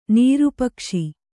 ♪ nīru pakṣi